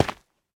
Minecraft Version Minecraft Version latest Latest Release | Latest Snapshot latest / assets / minecraft / sounds / block / basalt / step4.ogg Compare With Compare With Latest Release | Latest Snapshot
step4.ogg